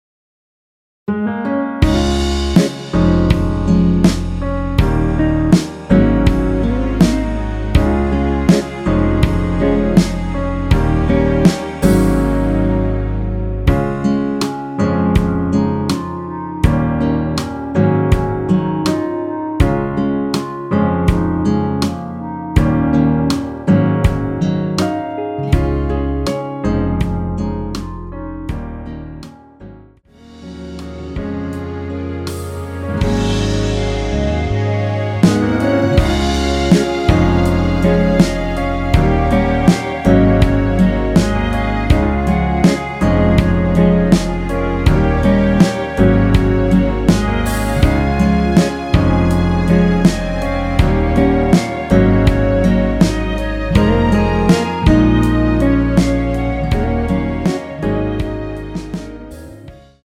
원키에서(-1)내린 멜로디 포함된 MR입니다.(미리듣기 확인)
Db
앞부분30초, 뒷부분30초씩 편집해서 올려 드리고 있습니다.
중간에 음이 끈어지고 다시 나오는 이유는